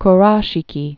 (k-räshē-kē)